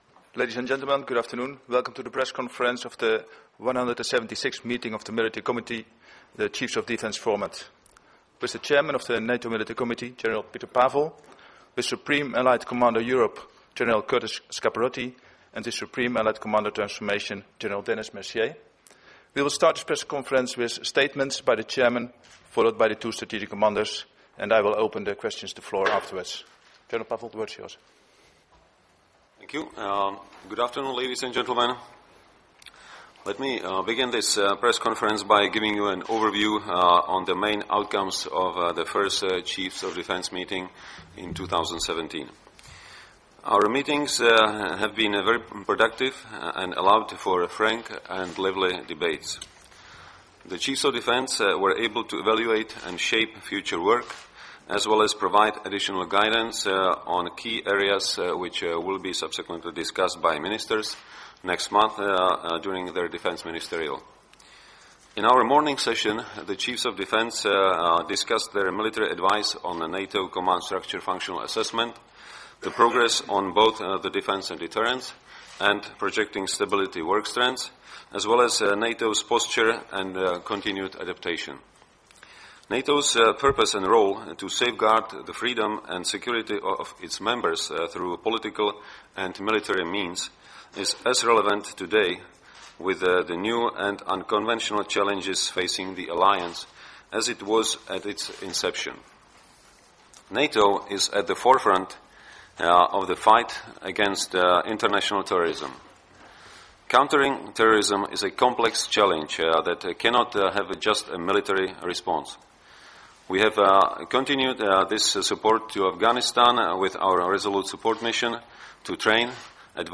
Opening remarks by Supreme Allied Commander Europe General Curtis M. Scaparrotti at the joint press conference with the Chairman of the Military Committee, General Petr Pavel and the Supreme Allied Commander Transformation, General Denis Mercier
Joint Press Conference by the Chairman of the Military Committee, Supreme Allied Commander Europe and Supreme Allied Commander Transformation